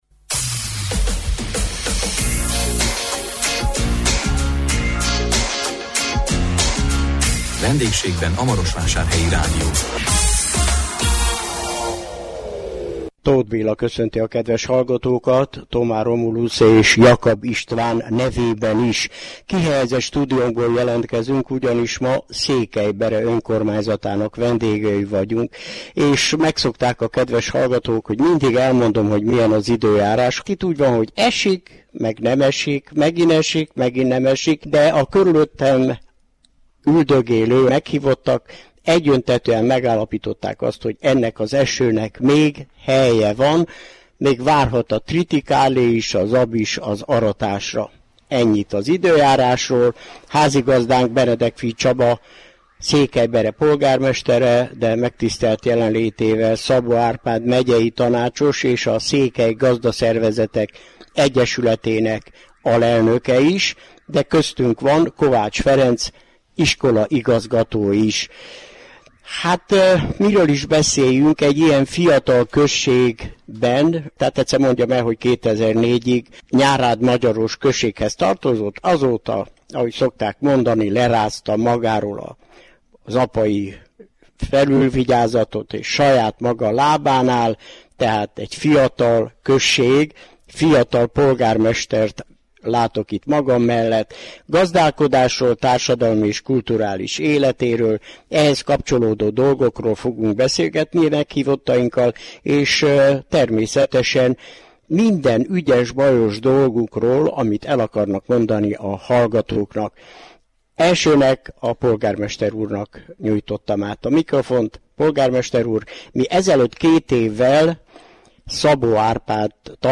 A 2019 augusztus 1-én jelentkező műsorunkban a Maros megyei Székelybere vendégei voltunk. Meghívottainkkal a megvalósításokról és tervekről, valamint a turizmus nyújtotta lehetőségekről beszélgettünk.